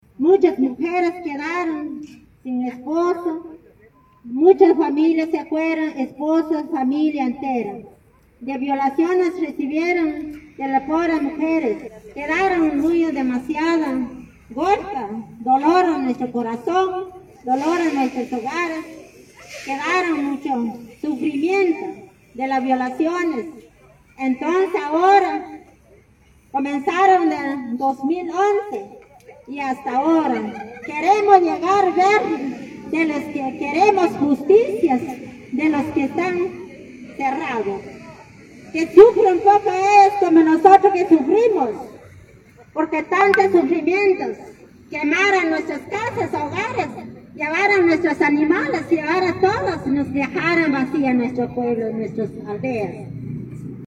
02-mujer-Achi.mp3